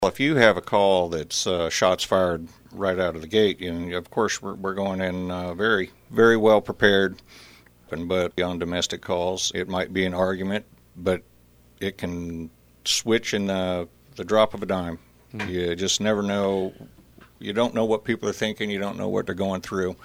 Emporia Police Chief Ed Owens, on the most recent installment of KVOE’s Monthly Q&A segment, says anytime an officer is injured or killed in the line of duty, it impacts all law enforcement agencies, including Emporia.